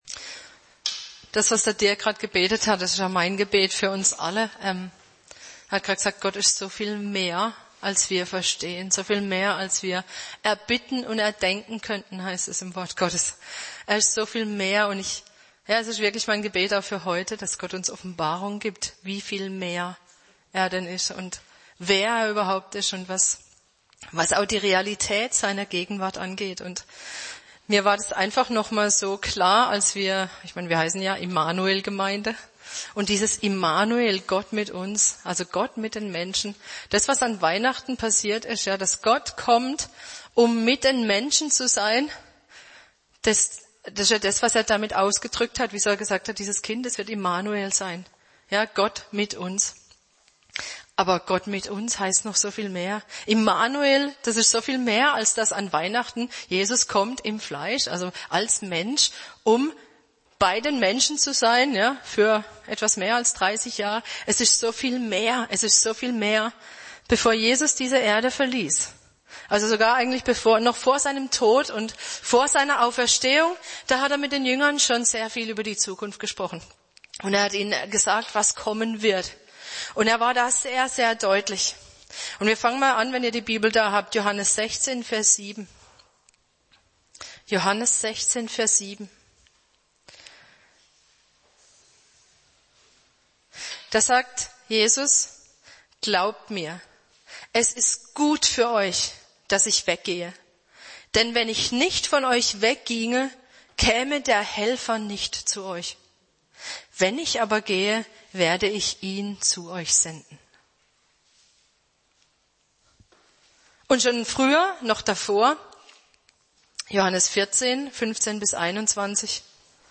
Predigt 07.01.2018: Gott ist IN mir!